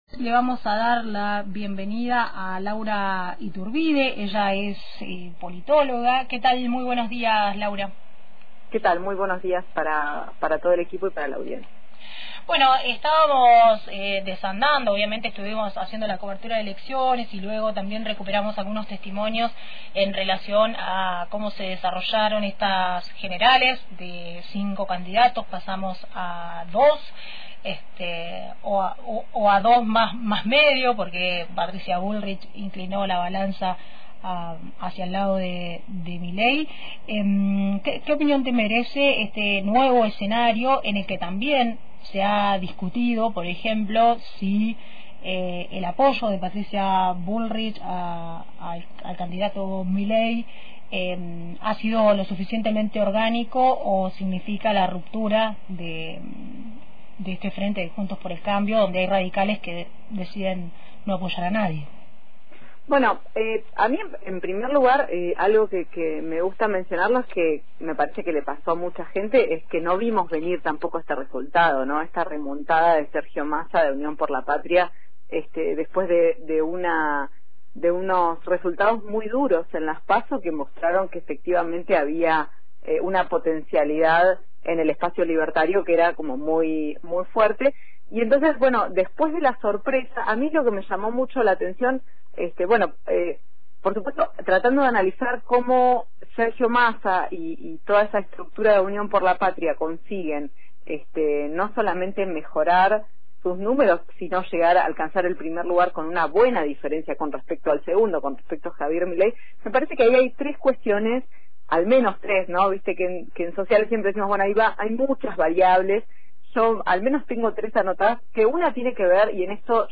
conversamos con la politóloga